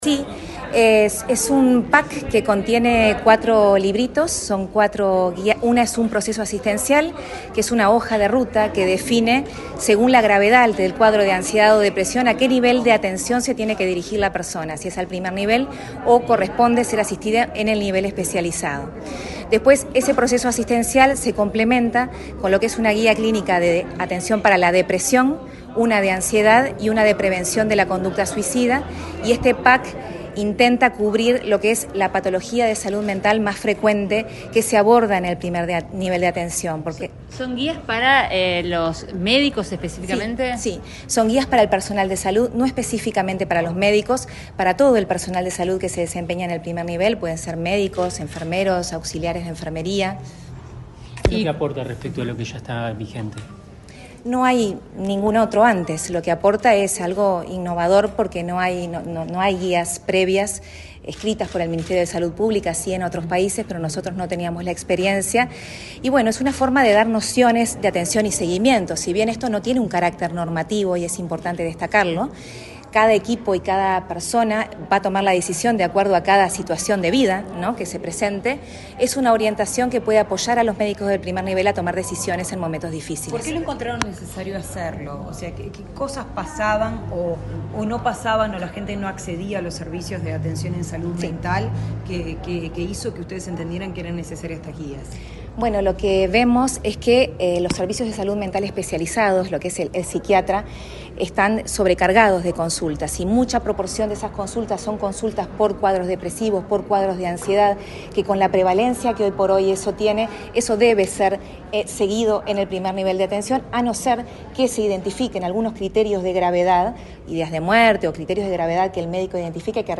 Declaraciones del programa de Salud Mental